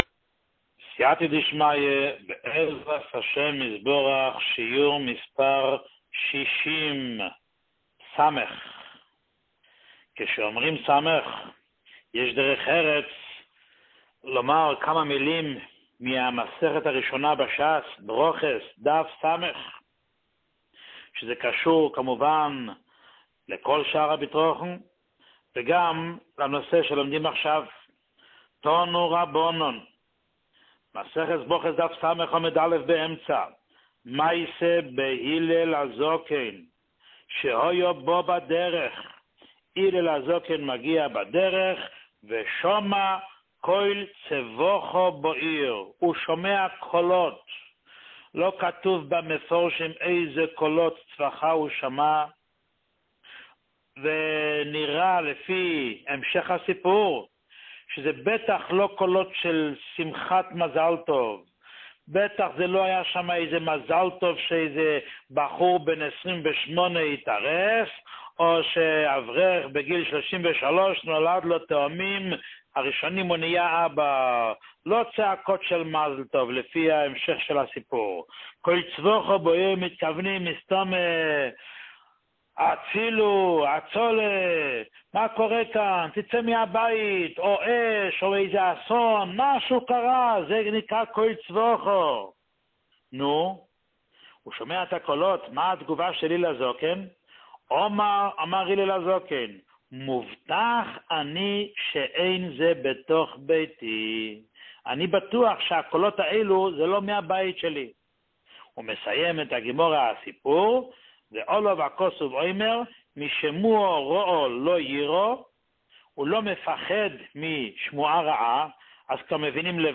שיעורים מיוחדים
שיעור 60